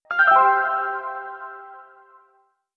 メニュー用効果音
決定音(16) タラリランン